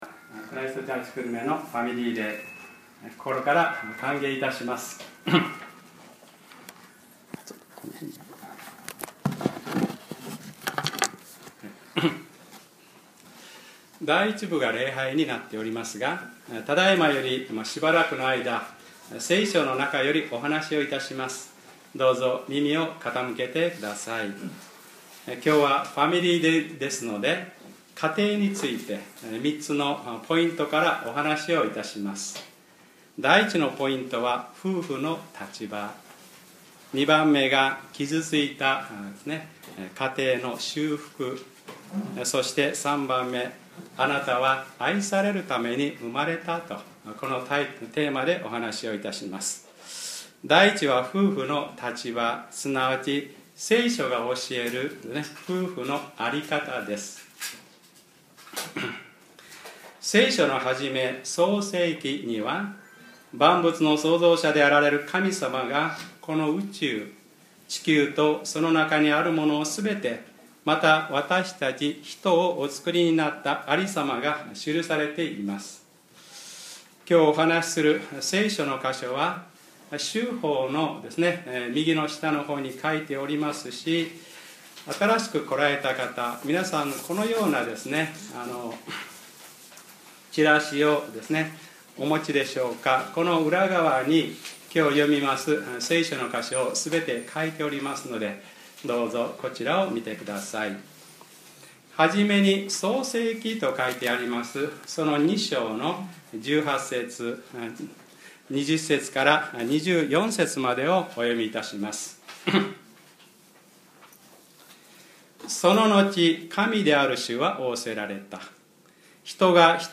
2015年06月21日（日）礼拝説教『ファミリーデイ：一番すぐれているのは愛です』